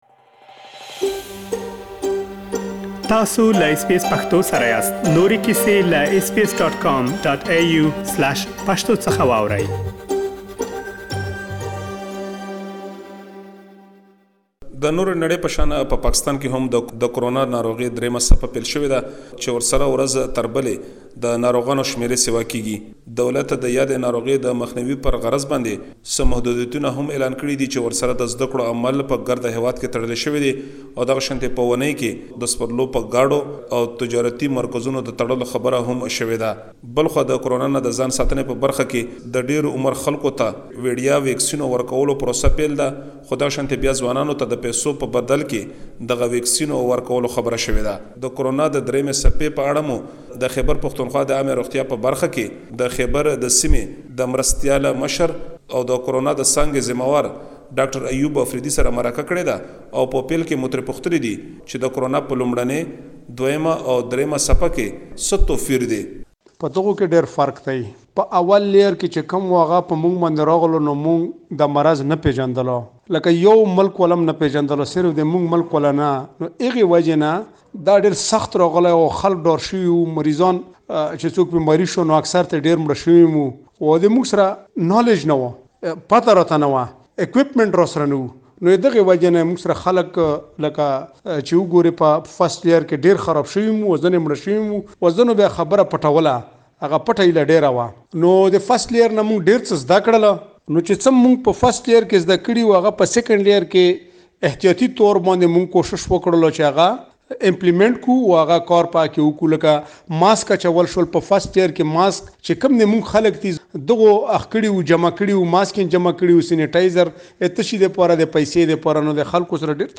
تاسو کولای شئ، بشپړه مرکه دلته واورئ.